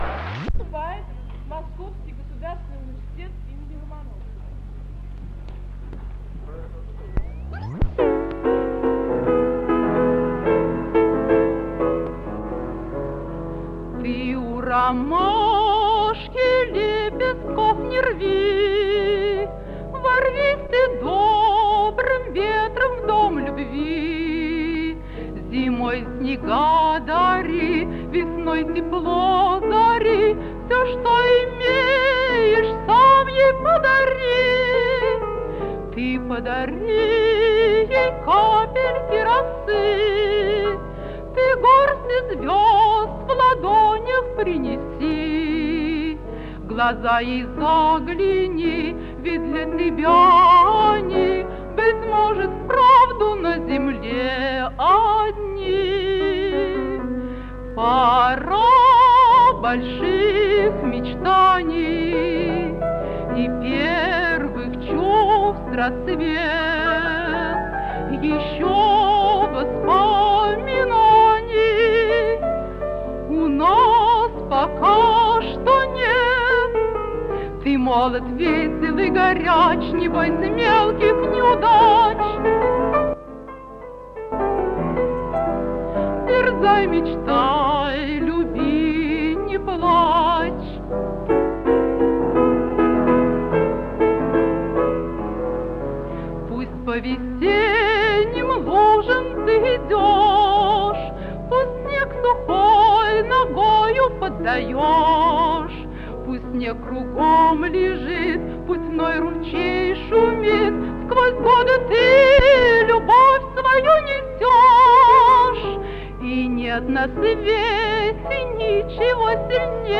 ВТОРОЙ МОСКОВСКИЙ ВЕЧЕР-КОНКУРС СТУДЕНЧЕСКОЙ ПЕСНИ